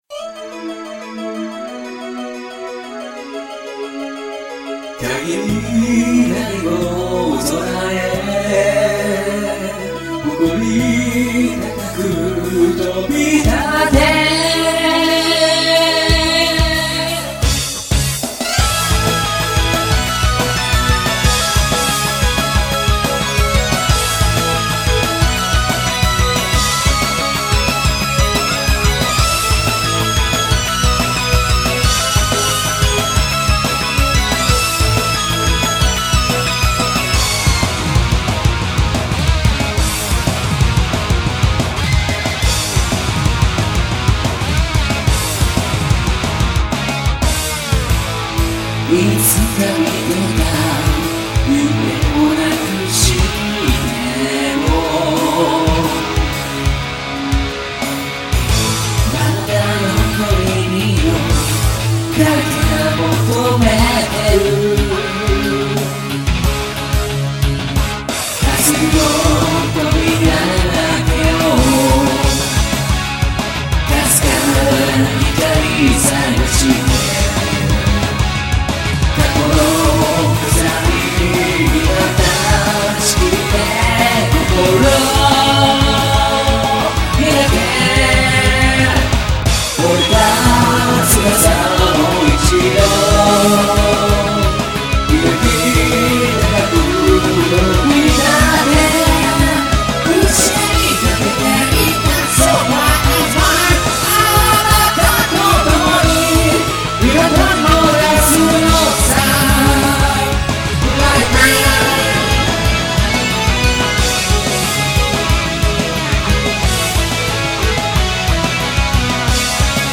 BPM181
Audio QualityPerfect (Low Quality)
This version is vocal-added version that released later.